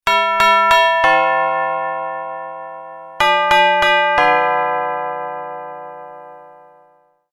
Beethoven Doorbell Chime